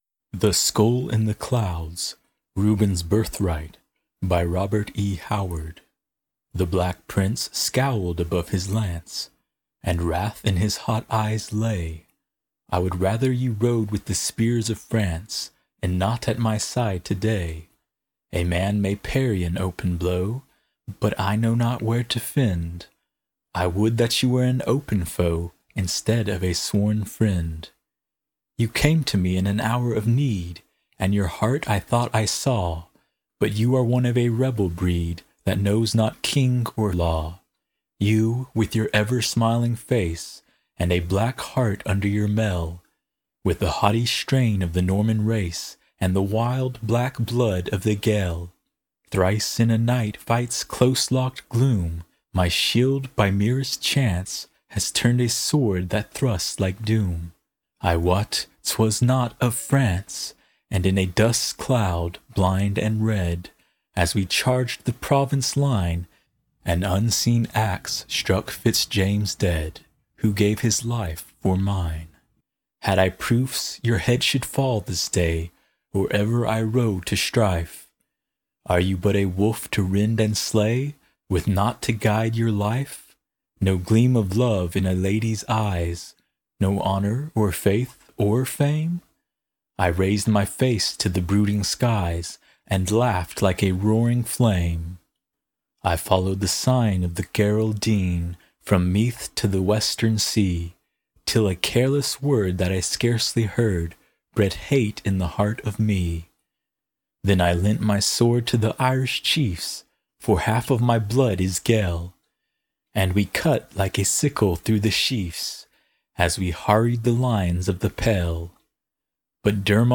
Audio Recordings of Poems by Robert E. Howard